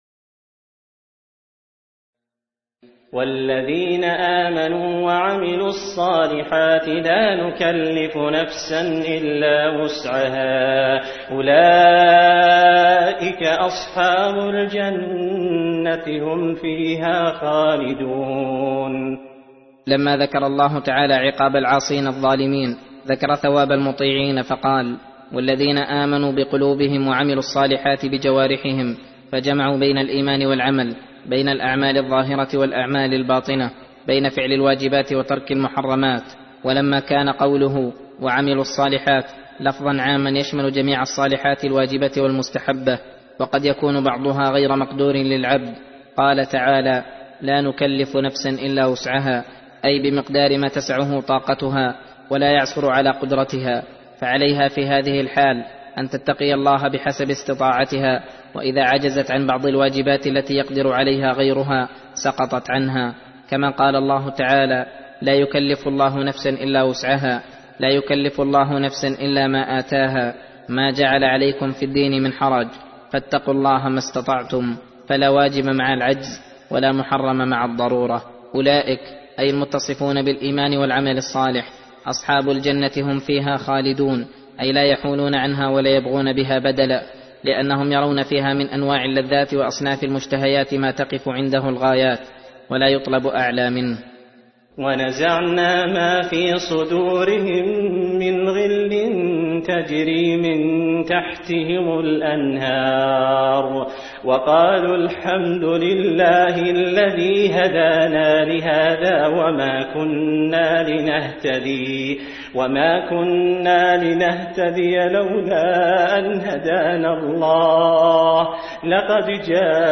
درس (3) : تفسير سورة الأعراف : (42-55)